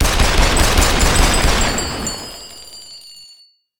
shells.ogg